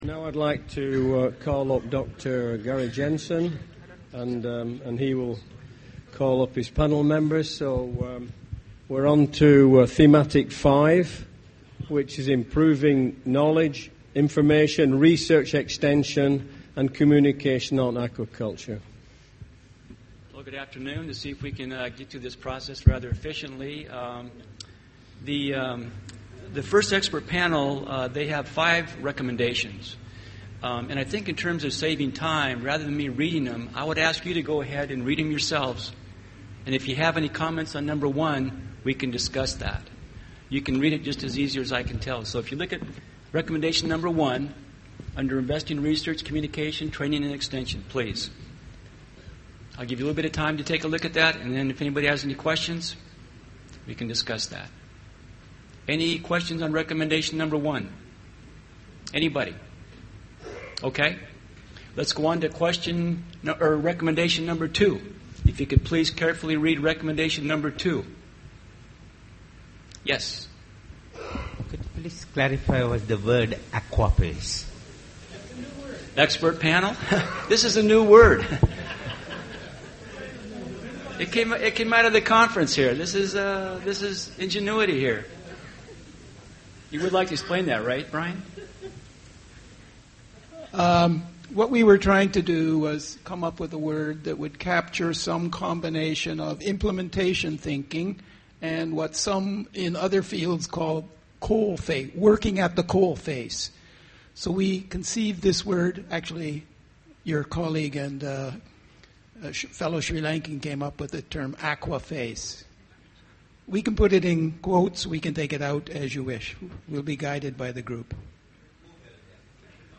Presentation of the summary, conclusions and recommendations of Thematic Session 5 (Improving knowledge, information, research, extension and communication on aquaculture).